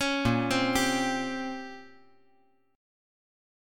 Bbm6add9 Chord
Listen to Bbm6add9 strummed